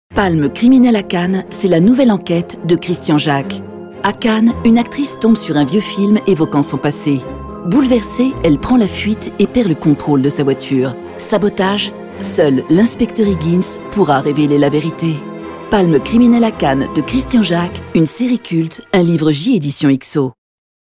droite